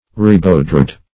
Search Result for " ribaudred" : The Collaborative International Dictionary of English v.0.48: Ribaudred \Rib"aud*red\, Ribaudrous \Rib"aud*rous\, a. Filthy; obscene; ribald.